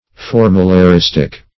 Search Result for " formularistic" : The Collaborative International Dictionary of English v.0.48: Formularistic \For`mu*la*ris"tic\, a. Pertaining to, or exhibiting, formularization.